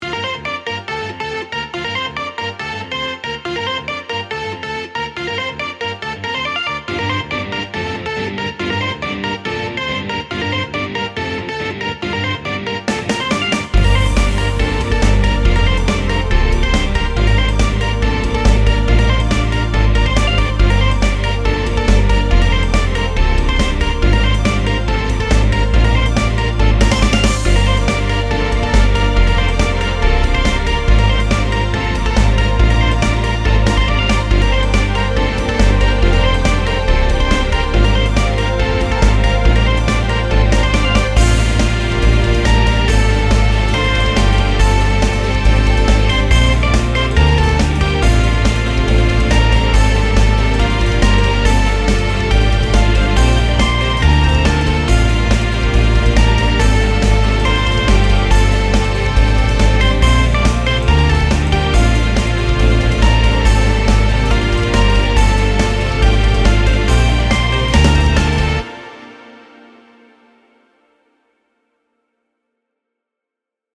It might suit a battle scene.